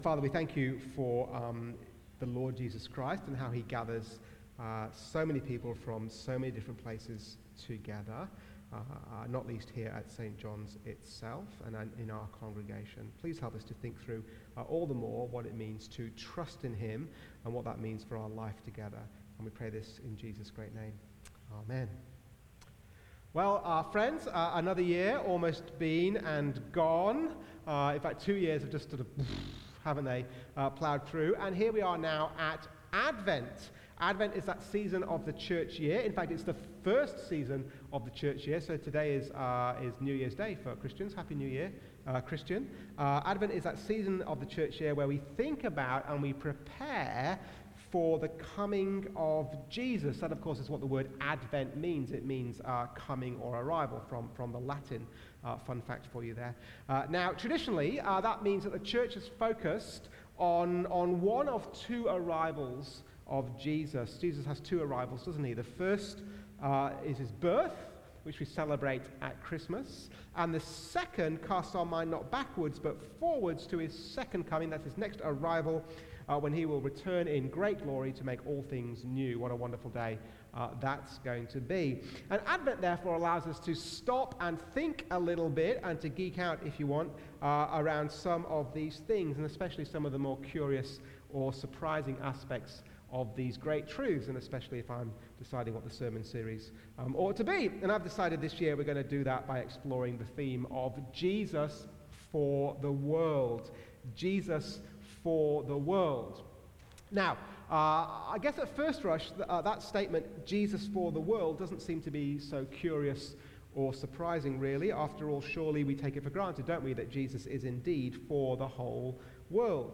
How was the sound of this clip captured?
from St John’s Anglican Cathedral Parramatta.